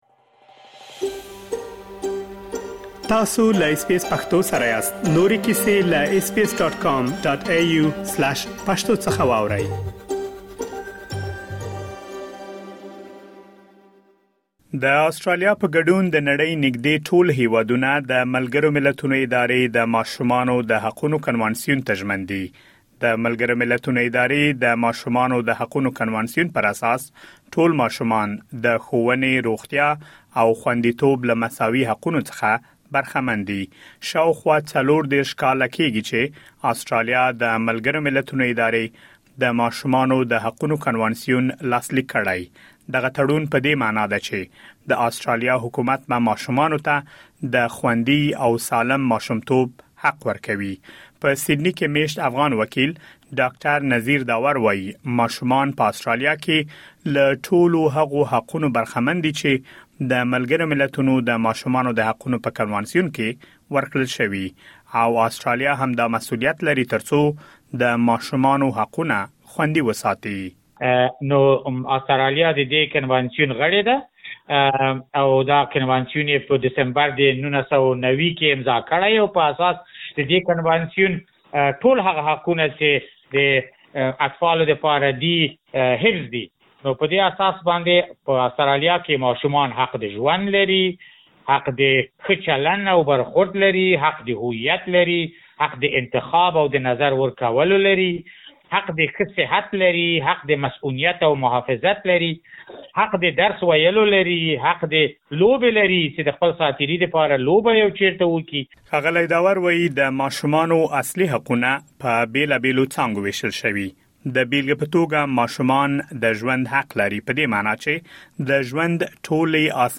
په دې اړه لا ډېر معلومات دلته په رپوټ کې اورېدلی شئ.